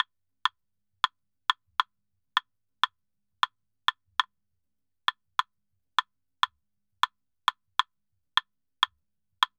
Claves_Salsa 100_3.wav